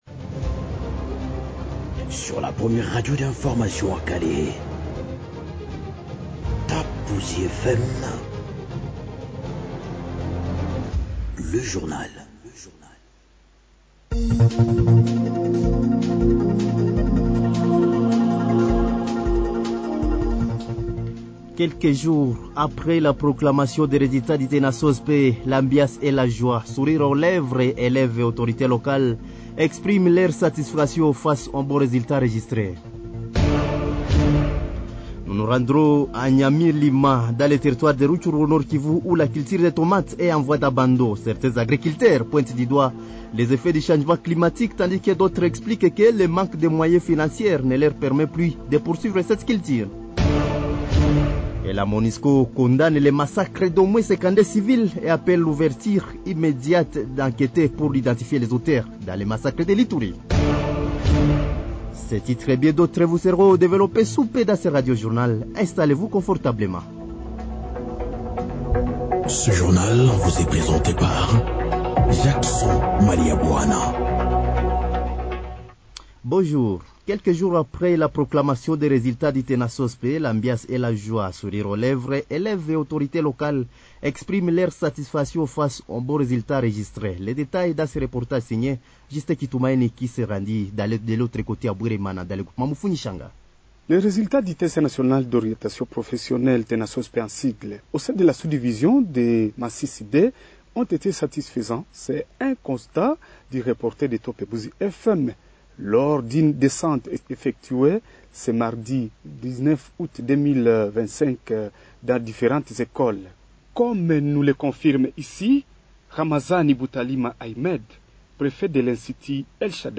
Journal
Journal français de 6h30 de ce mercredi 20 août 2025